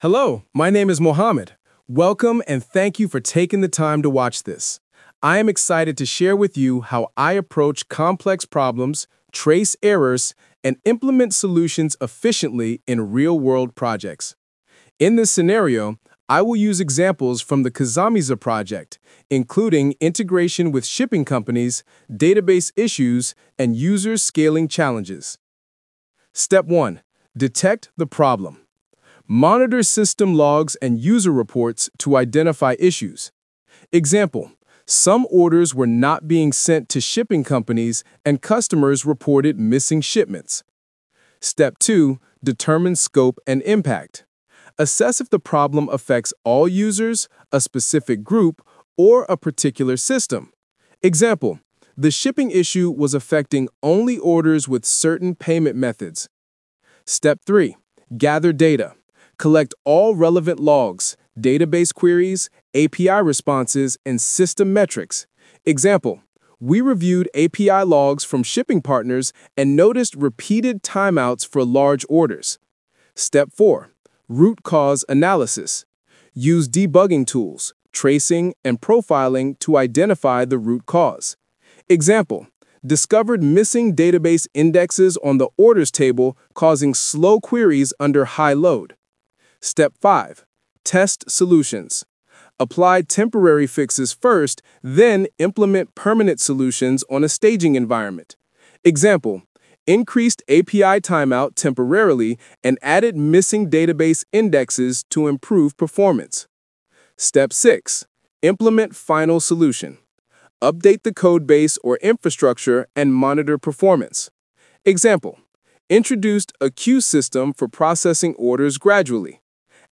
🎙 How I Solve & Trace Problems – "AI-Generated Voice"